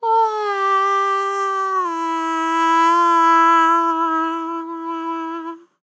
MOUTH BRASS Sample
Categories: Vocals Tags: BRASS, dry, english, fill, LOFI VIBES, male, MOUTH, sample
MAN-LYRICS-FILLS-120bpm-Am-16.wav